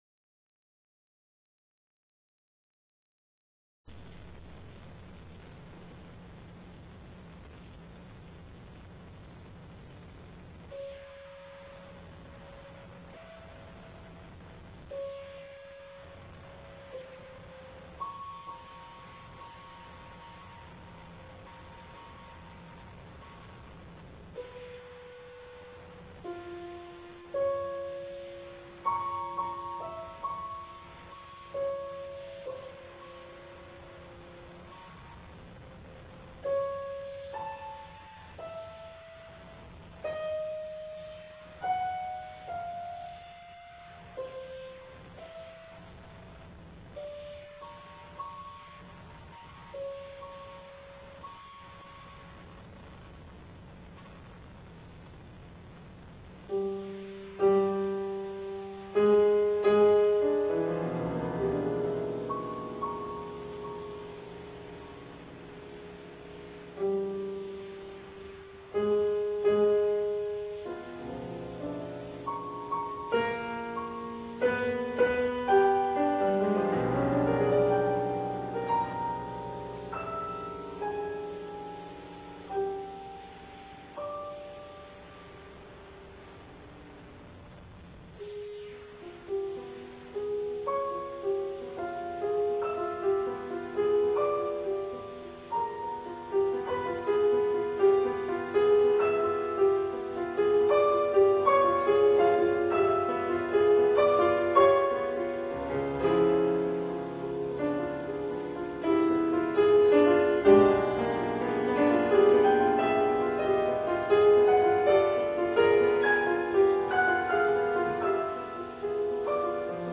この演奏会は福井の「ユー・アイふくい」多目的ホールで行なわれました。
当日はトークを交えて楽しいコンサートとなりましたが、自編曲が私のピアノのテクニックを超えるハードだったため、体力的にも限界状態の演奏もあります。